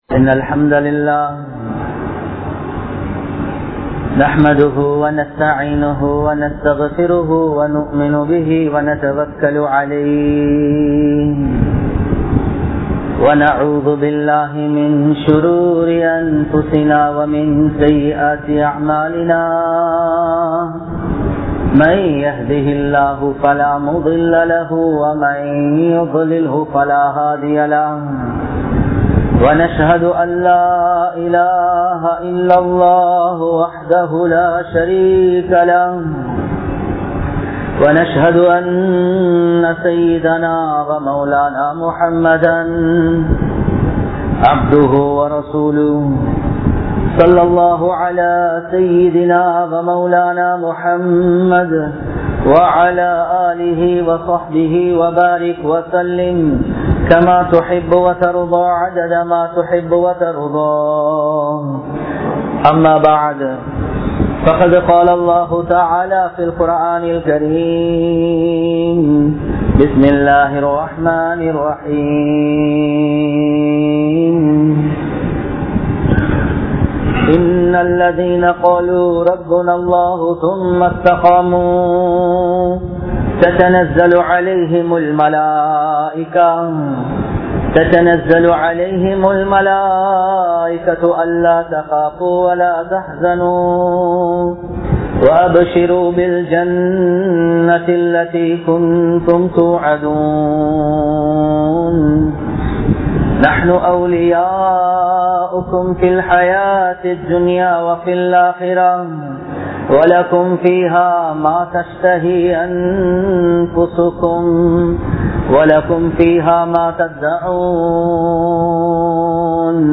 Dhawaththai Vittaal (தஃவத்தை விட்டால்) | Audio Bayans | All Ceylon Muslim Youth Community | Addalaichenai
Colombo 03, Kollupitty Jumua Masjith